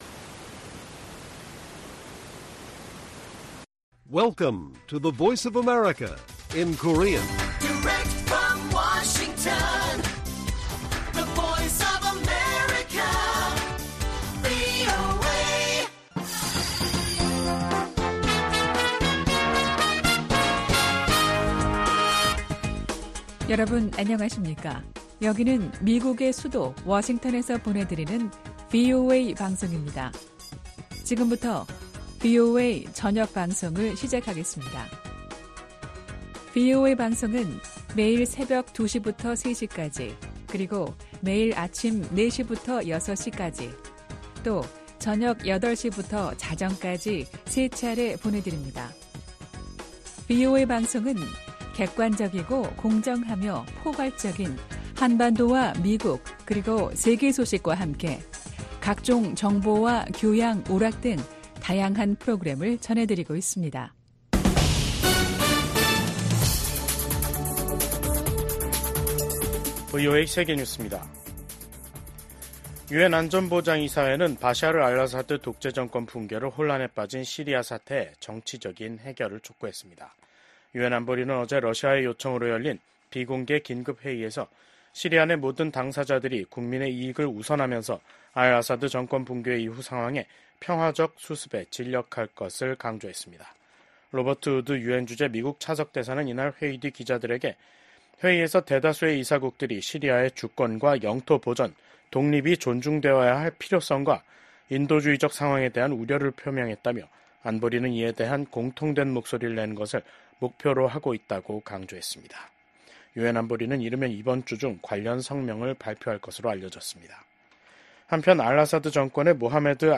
VOA 한국어 간판 뉴스 프로그램 '뉴스 투데이', 2024년 12월 10일 1부 방송입니다. 비상계엄 사태를 수사하는 한국 검찰은 이 사태를 주도한 혐의를 받고 있는 김용현 전 국방부 장관에 대해 구속영장을 청구했습니다. 미국 국무부는 한국의 정치적 혼란 상황이 법치에 따라 해결돼야 한다는 원칙을 재확인했습니다.